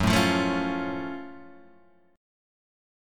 F# Minor Major 7th